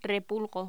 Locución: Repulgo
voz
Sonidos: Voz humana